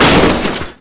CRASH7.mp3